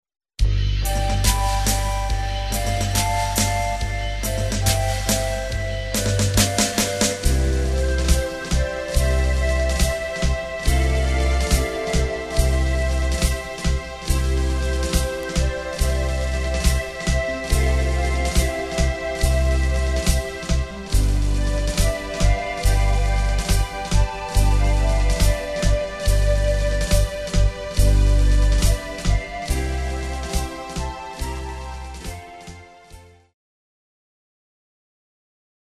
Christmas Karaoke Soundtrack
Backing Track without Vocals for your optimal performance.